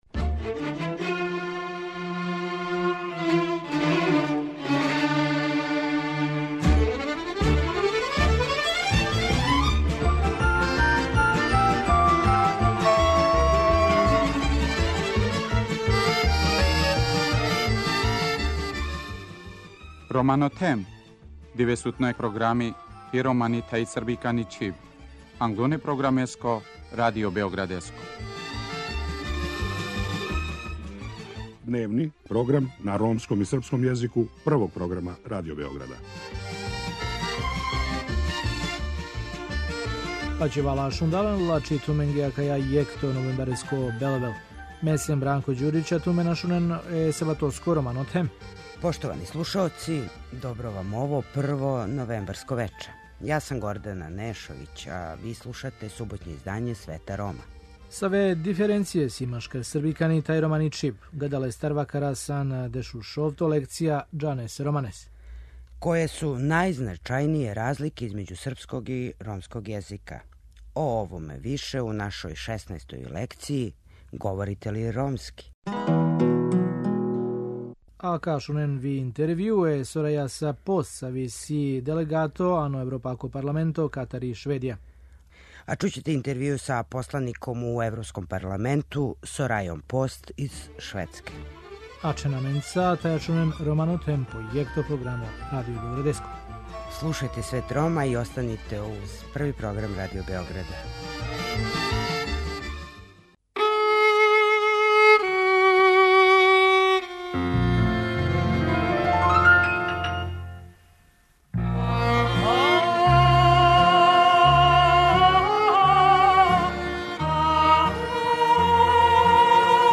Гошћа емисије је посланица у Европском парламенту Сораја Пост из Шведске.